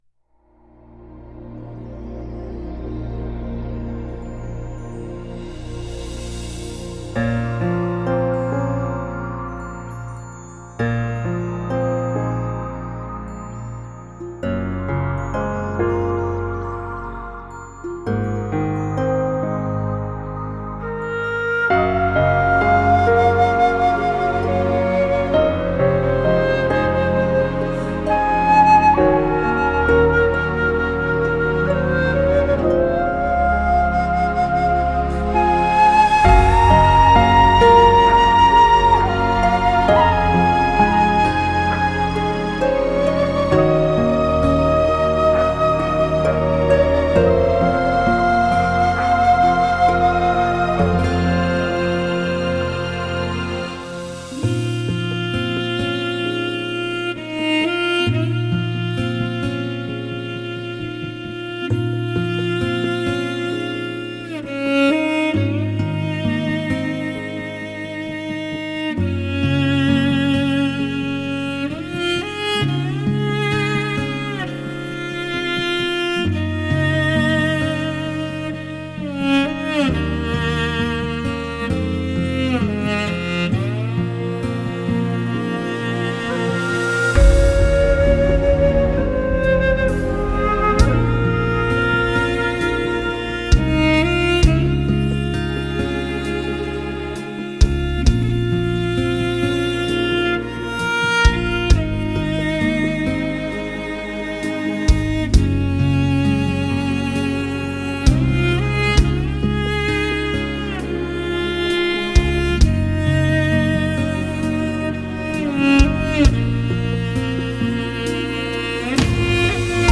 Genre: Instrumental, Traditional Folk, Morin Khuur